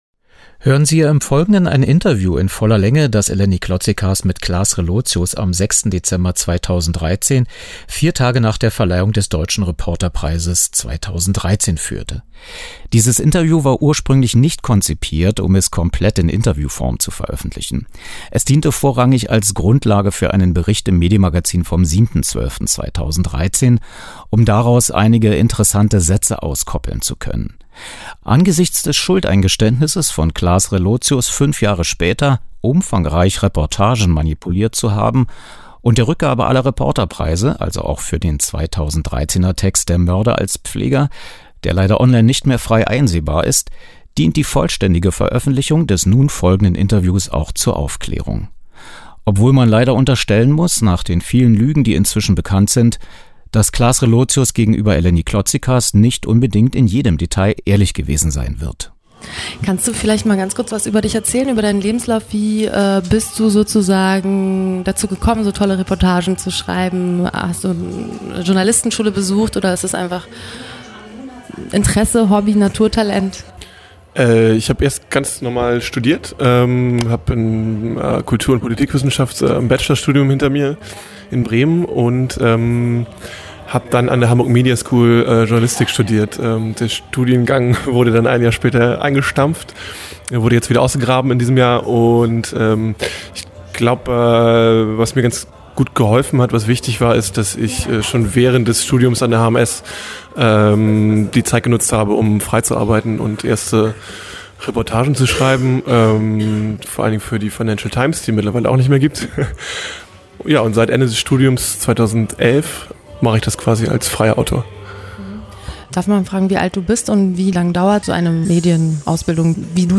Was: Interview nach der Reporterpreisverleihung 2013
Wo: Berlin